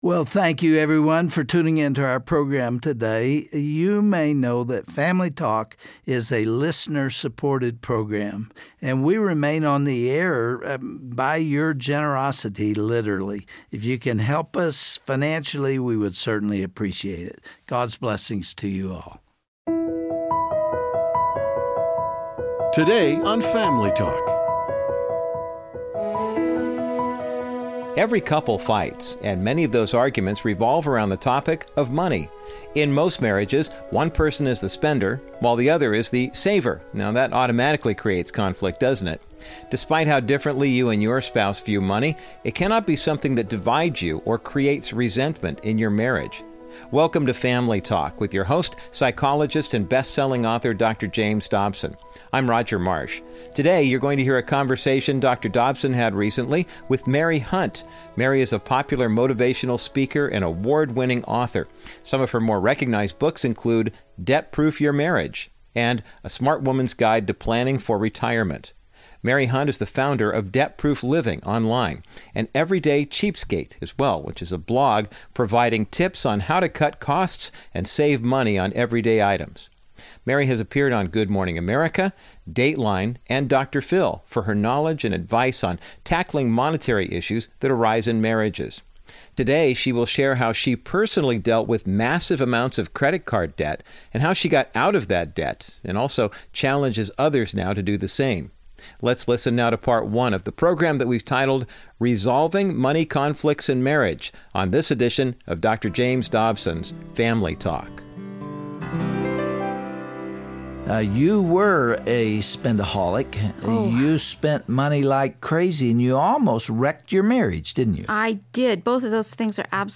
Many arguments between a husband and wife stem from the issue of money. On this Family Talk broadcast